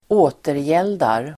Uttal: [²'å:terjel:dar]